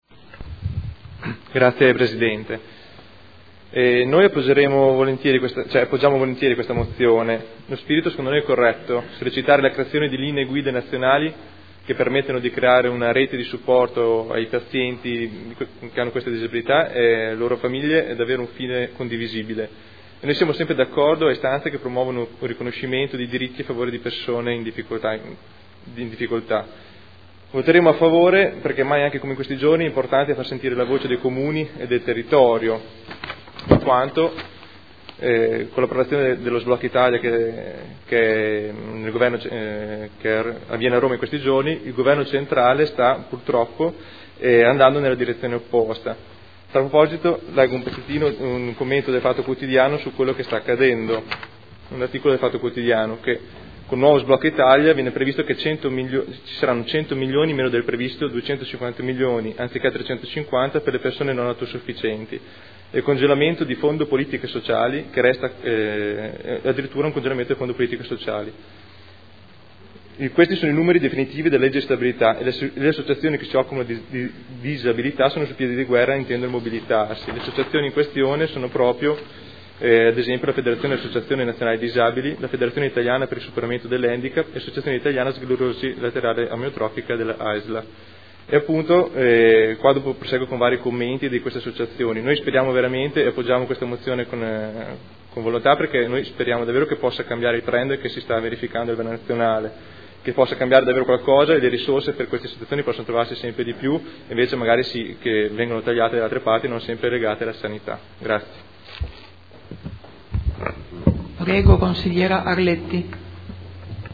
Marco Rabboni — Sito Audio Consiglio Comunale